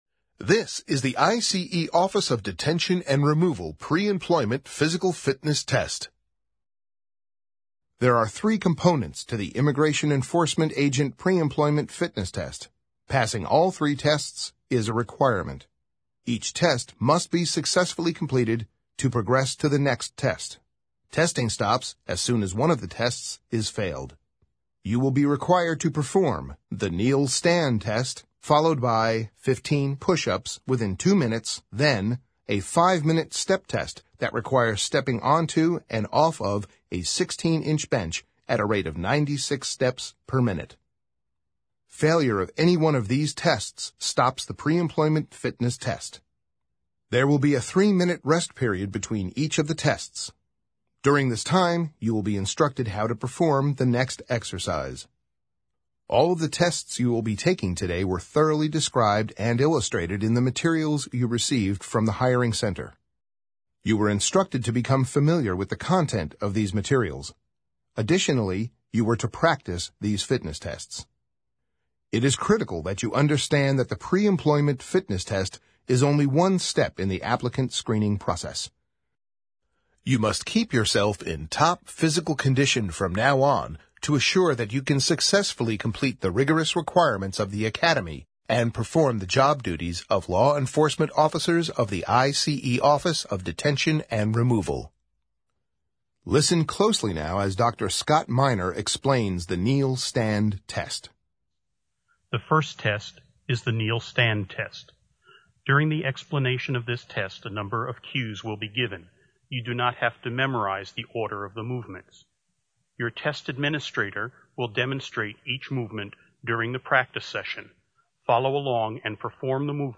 Immigration Enforcement Agent Narrated Preemployment Fitness Test - Test Administrator Version